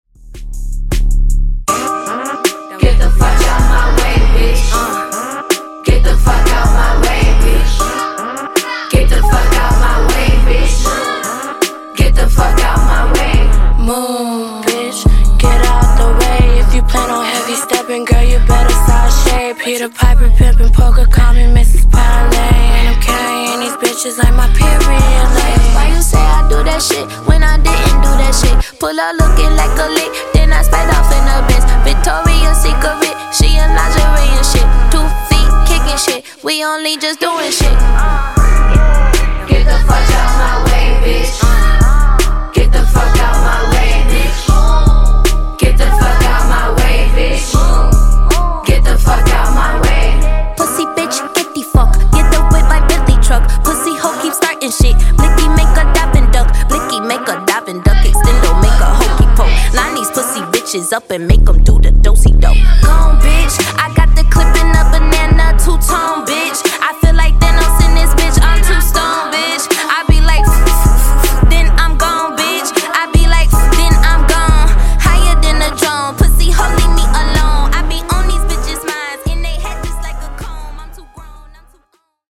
DANCE , RE-DRUM , TOP40